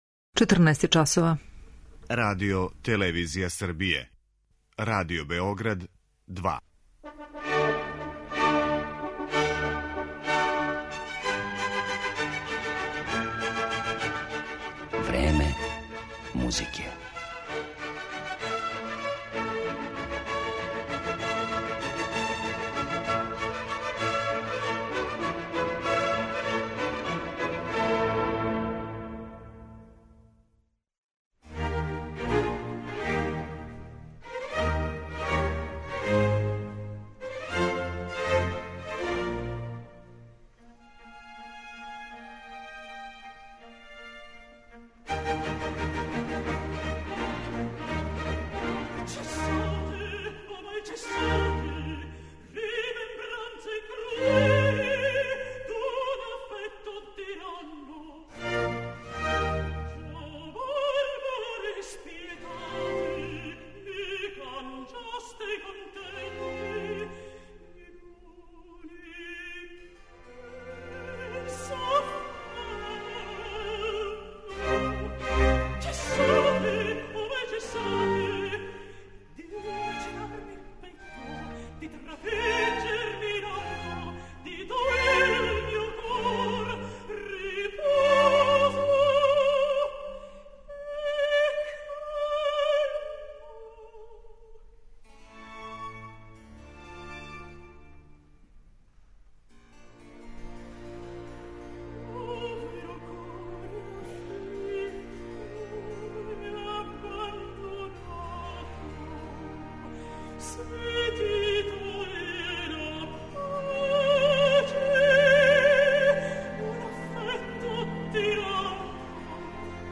Њен глас стручњаци једногласно дефинишу као прави контраалт и коју без изузетка описују као редак феномен у вокалној уметности.